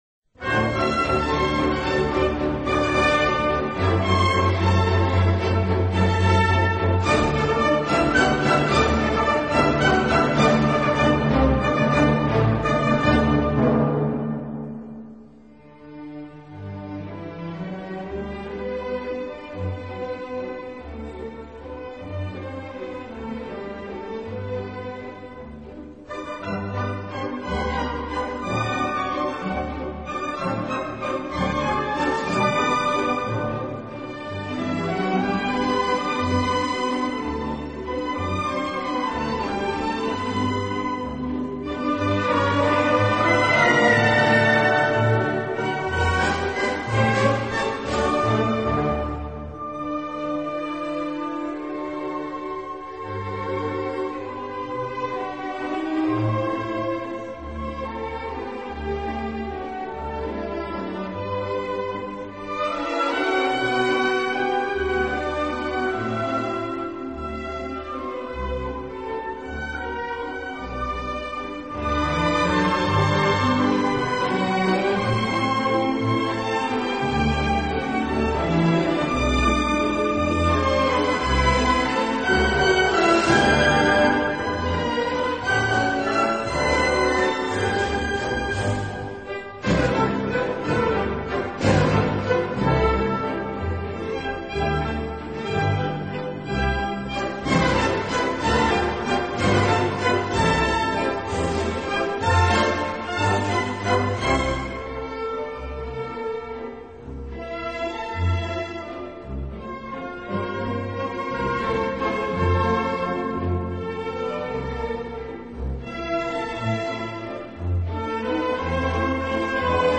音乐类型：Classic 古典
音乐风格：Classical,Waltz